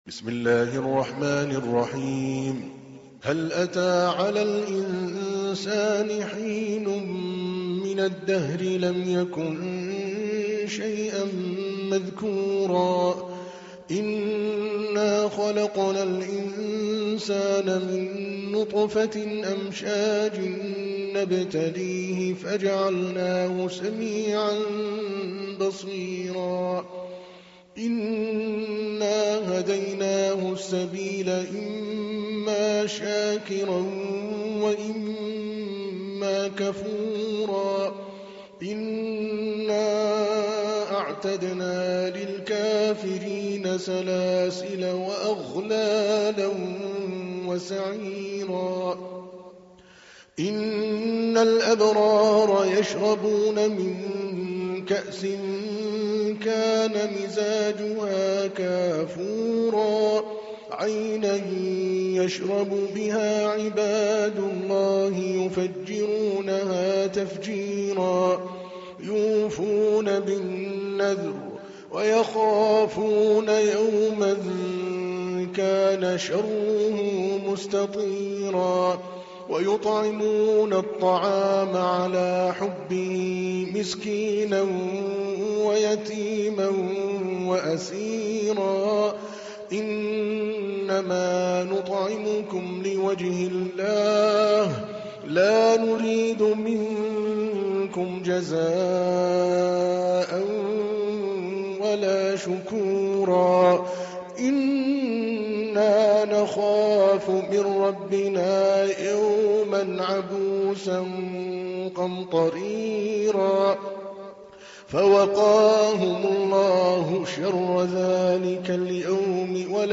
تحميل : 76. سورة الإنسان / القارئ عادل الكلباني / القرآن الكريم / موقع يا حسين